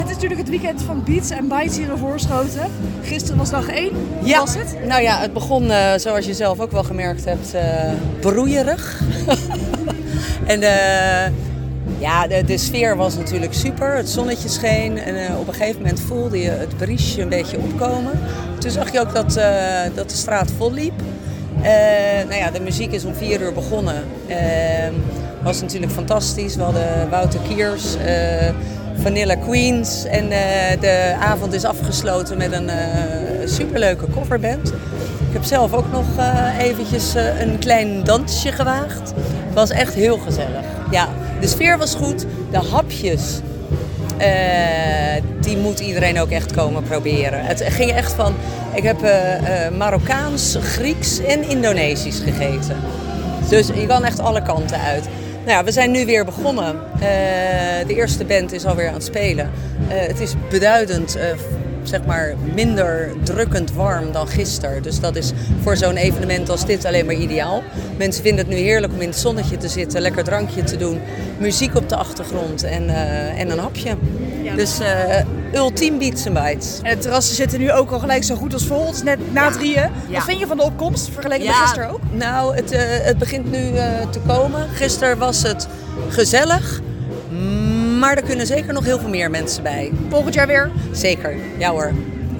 Verslaggever
in gesprek met